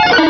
pokeemerald / sound / direct_sound_samples / cries / wigglytuff.aif